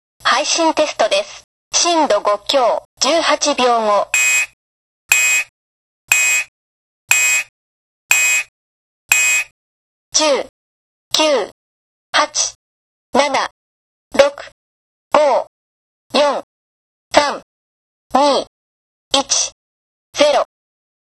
緊急地震速報アナウンス
緊急地震速報アナウンス音 震度５強 (WAV形式 約3.6MB）